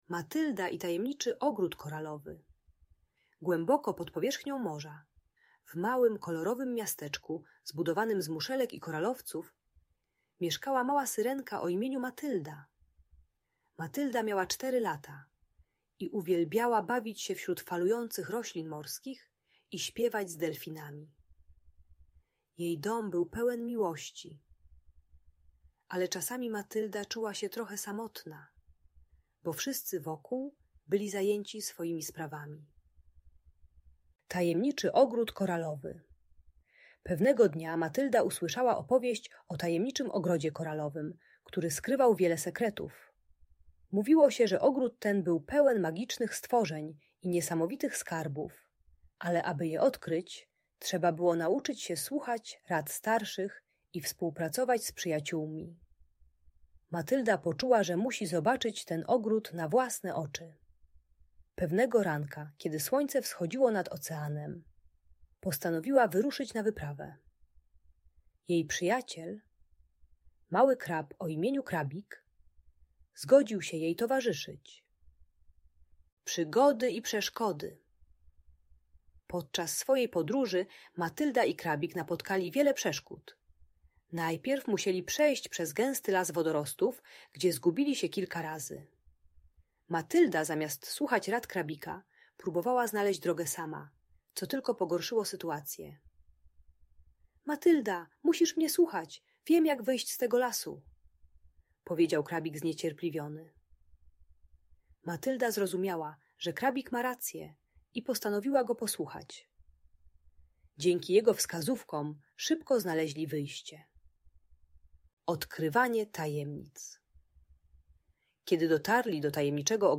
Historia Matyldy i tajemniczego ogrodu - Bunt i wybuchy złości | Audiobajka